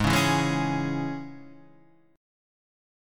G# Suspended 4th Sharp 5th